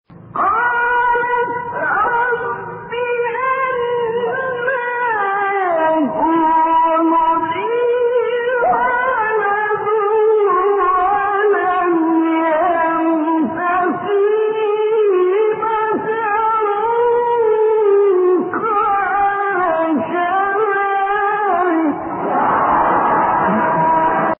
شبکه اجتماعی: مقاطع صوتی از تلاوت قاریان برجسته مصری ارائه می‌شود.
مقطعی از ابوالعینین شعیشع/ سوره آل‌عمران در مقام سه گاه